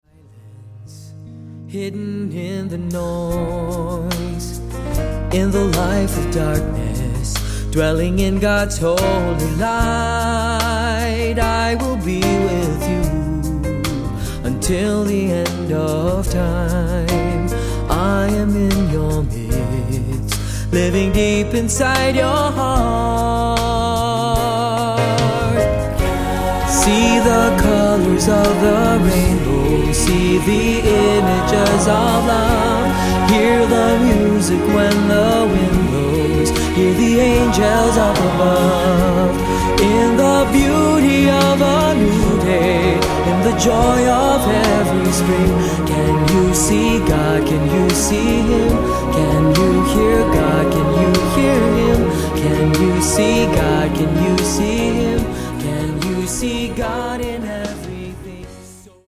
Accompaniment:      Piano
Music Category:      Christian